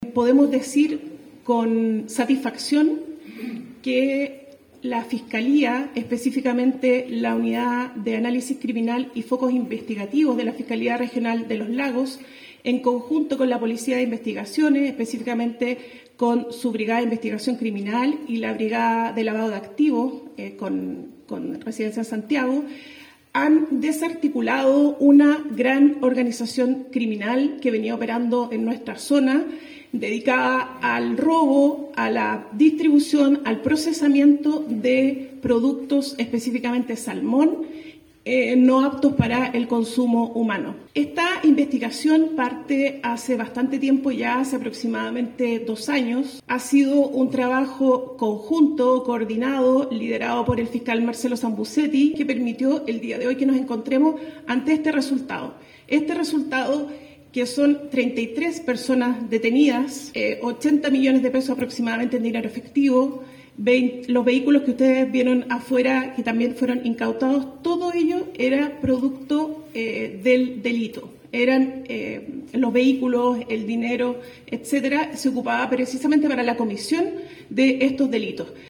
La fiscal regional Carmen Gloria Wittwer manifestó que la investigación que culminó con este exitoso operativo, comenzó aproximadamente hace dos años.